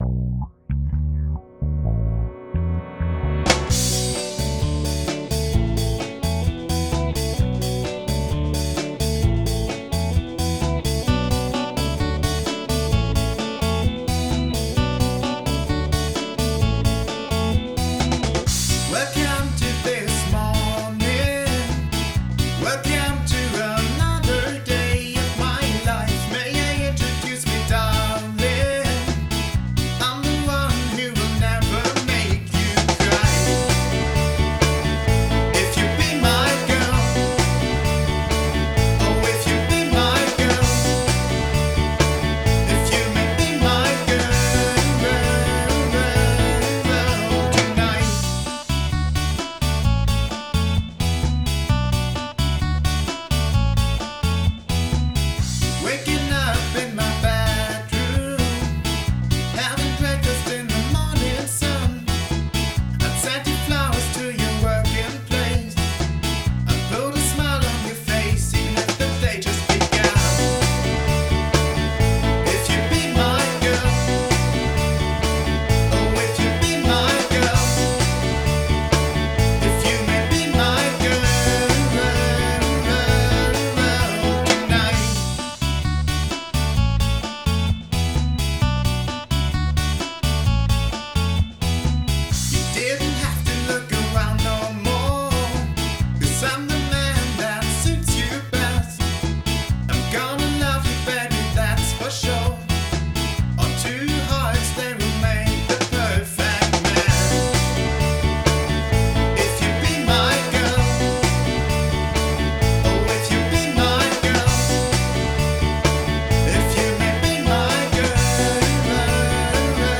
• Genre: Poprock